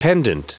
Prononciation du mot pendant en anglais (fichier audio)
Prononciation du mot : pendant